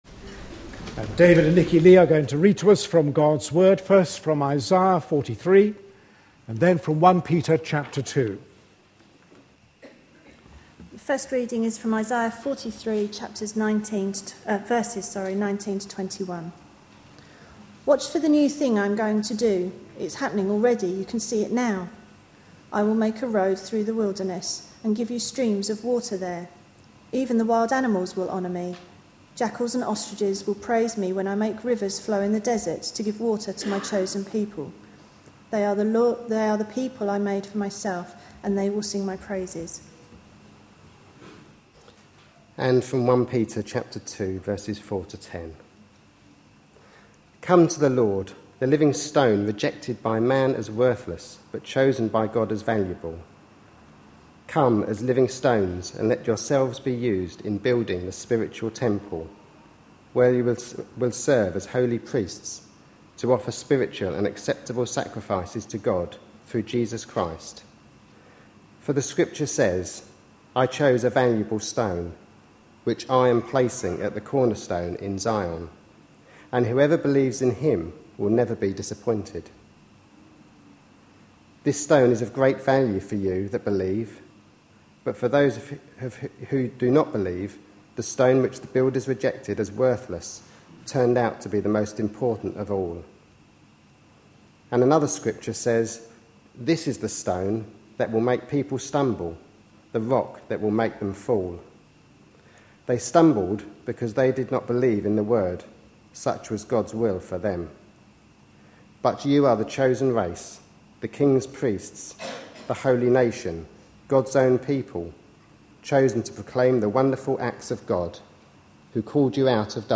A sermon preached on 2nd September, 2012, as part of our The Message of Peter for Today series.